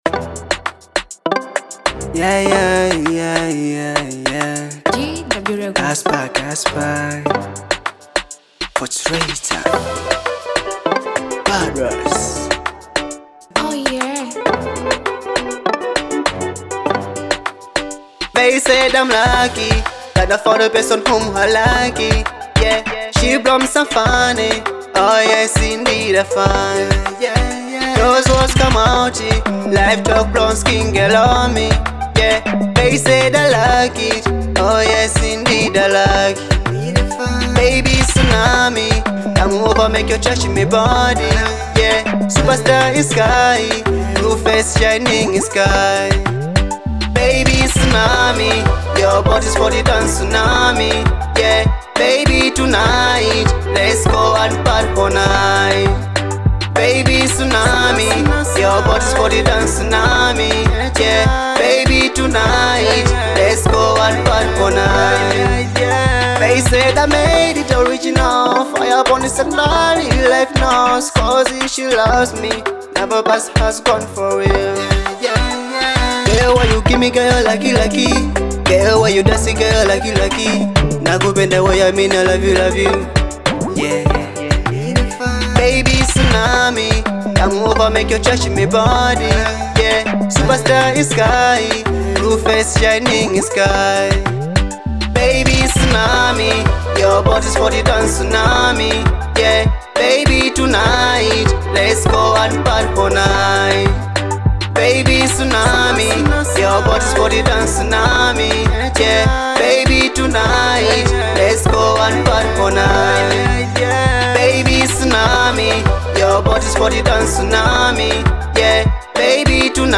Reggae-Dancehall